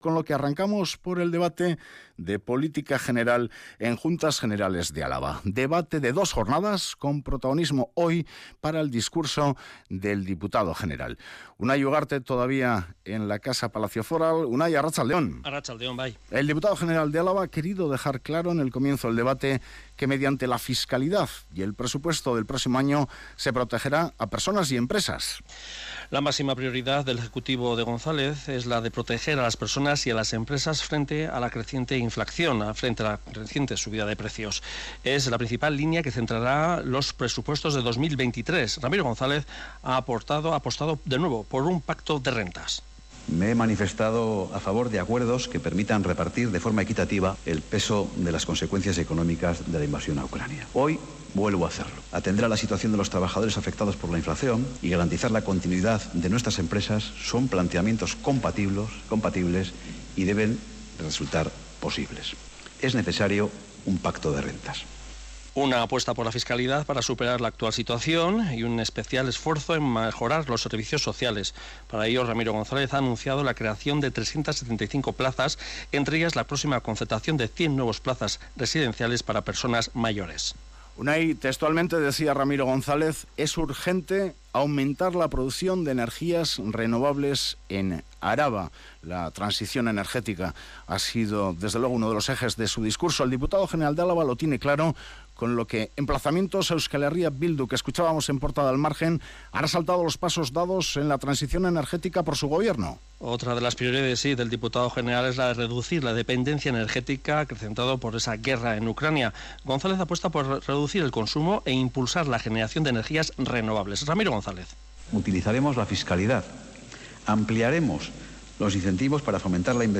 El diputado general de Álava, Ramiro González, ha abierto este lunes el pleno de Política General de la Diputación alavesa situando como principales compromisos la "protección" de familias